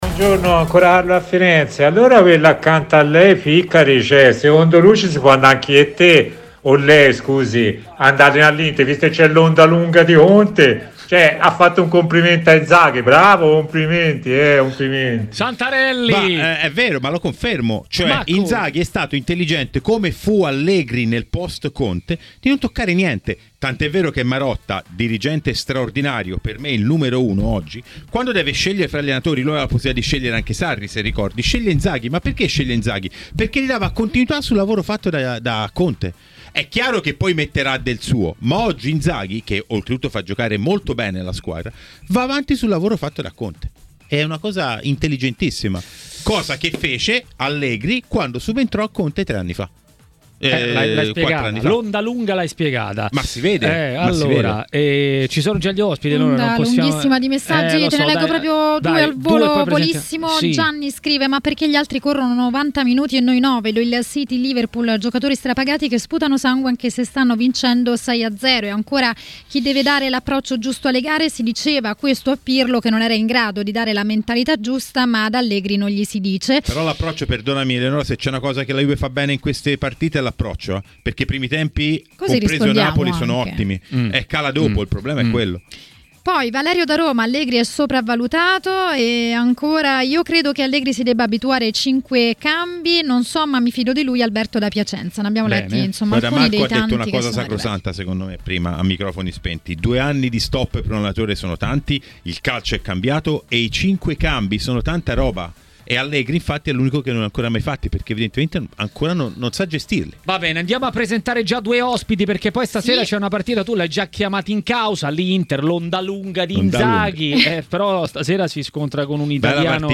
A parlare del turno infrasettimanale a TMW Radio, durante Maracanà, è stato mister Gigi Cagni.